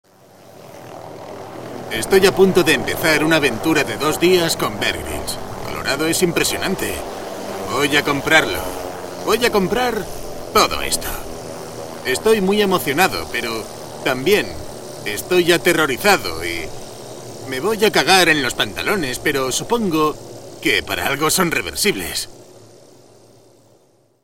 kastilisch
Sprechprobe: Sonstiges (Muttersprache):
Voice talent specialized in Dubbing Movies, Documentaries & Cartoons